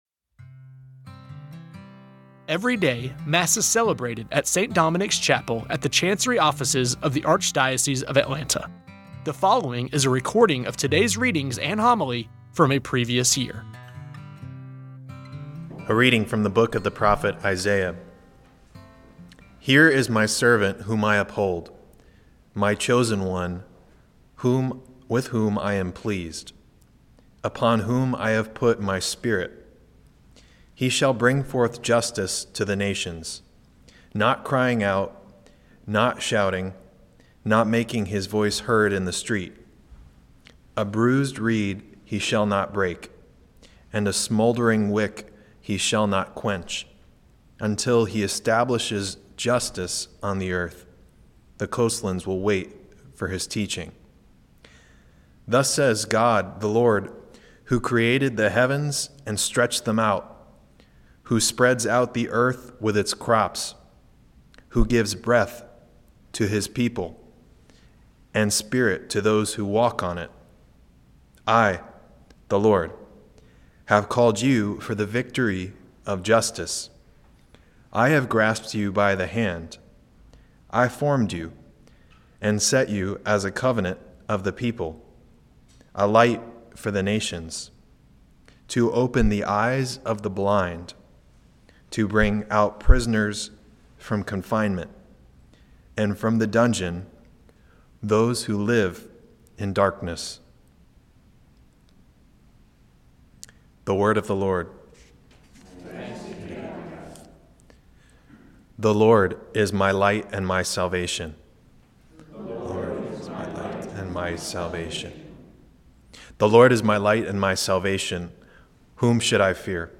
Every day, Mass is celebrated at St. Dominic’s Chapel at the Chancery Offices of the Archdiocese of Atlanta. The following is a recording of today’s readings and homily from a previous year. You may recognize voices proclaiming the readings and homilies as employees, former employees, or friends of the Archdiocese.